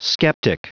Prononciation du mot skeptic en anglais (fichier audio)
Prononciation du mot : skeptic